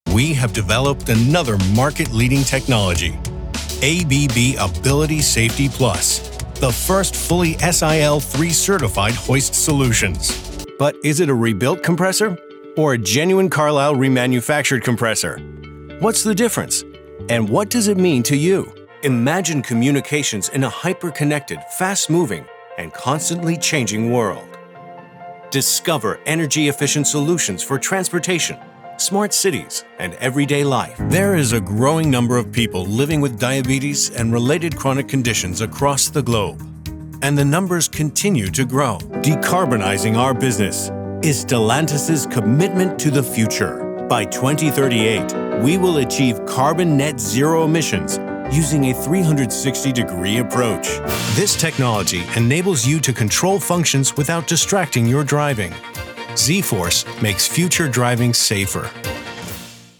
Professional voice over studio with GIK acoustic treatment.
Sprechprobe: Industrie (Muttersprache):